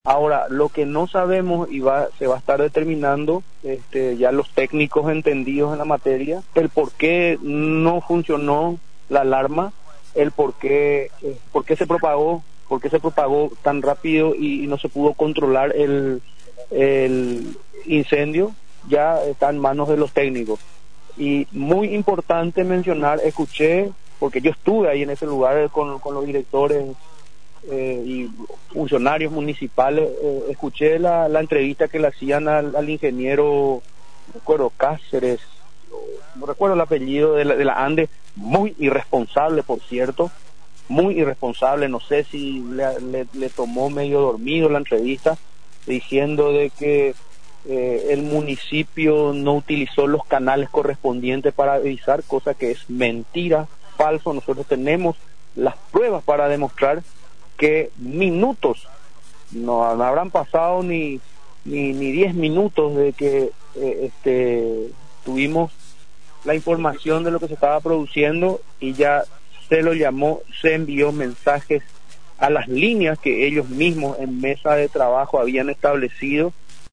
Asunción, Radio Nacional.-El origen del incendio que se produjo en la noche del lunes en el Mercado 4 se está investigando, afirmó este martes el intendente de Asunción, Oscar Rodríguez.
42-INT.-OSCAR-RODRIGUEZ.mp3